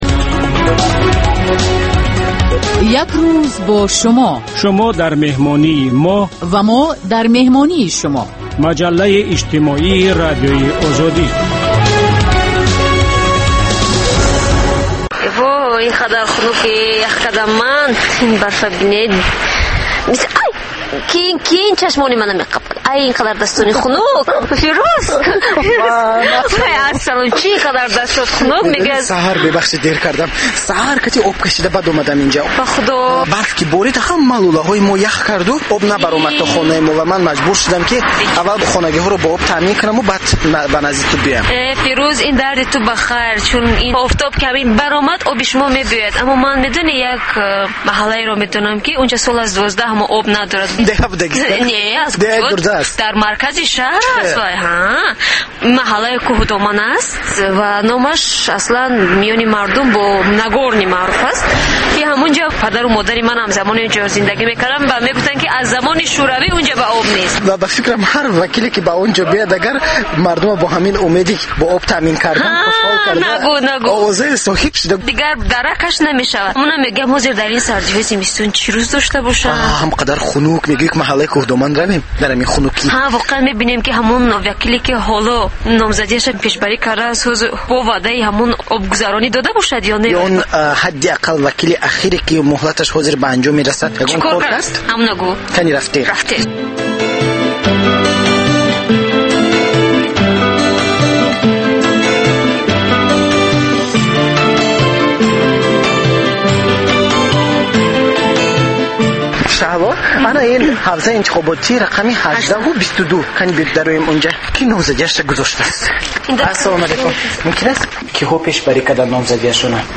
"Як рӯз бо Шумо" барои дарки рӯзгори Шумо. Маҷаллаи ғайриодӣ, ки ҳамзамон дар шакли видео ва гуфтори радиоӣ омода мешавад.